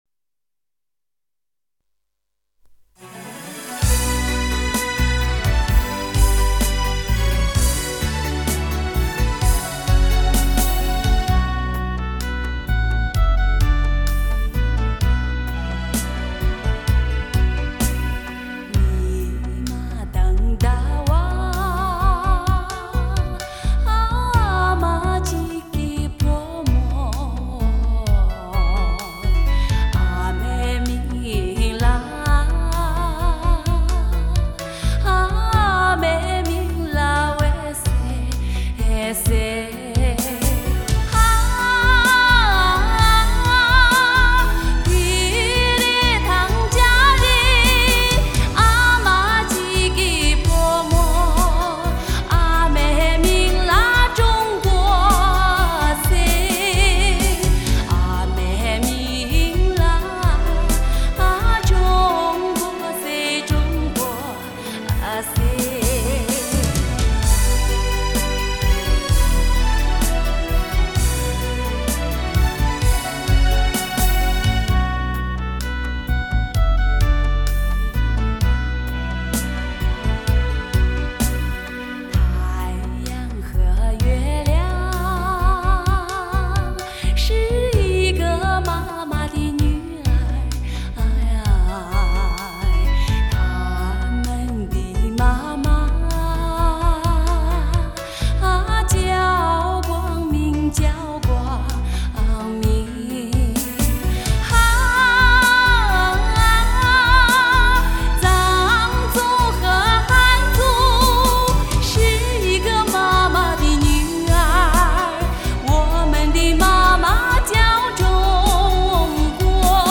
其演唱风格流畅自然，
既有浓郁的民族特色又充满时代气息。